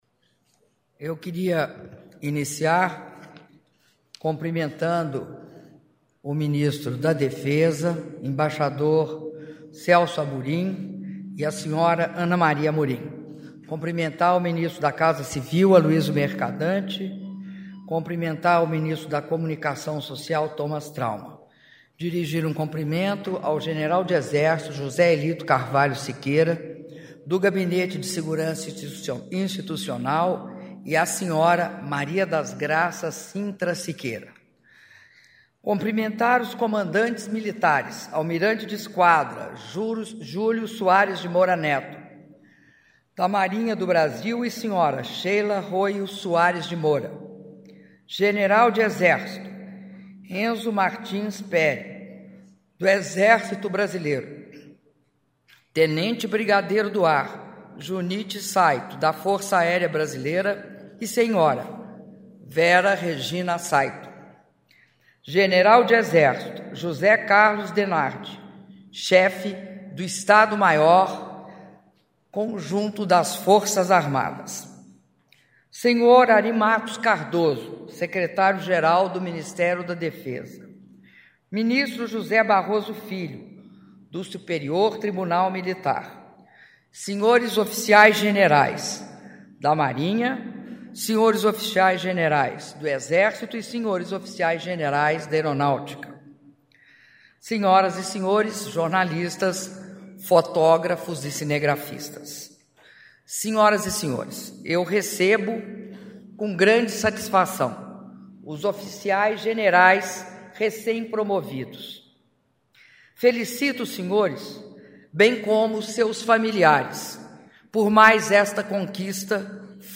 Áudio do discurso da Presidenta da República, Dilma Rousseff, durante Apresentação de Oficiais-Generais promovidos - Brasília/DF (4min22s)